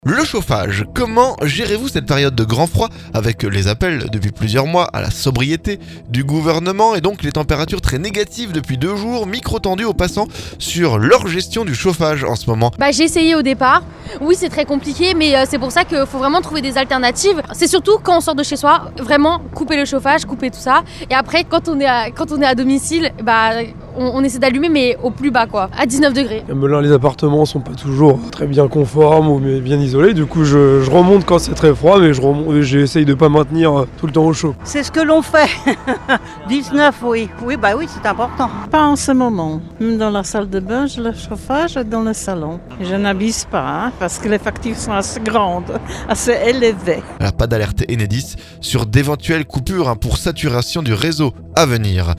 Micro tendu aux passants sur leur gestion du chauffage en ce moment.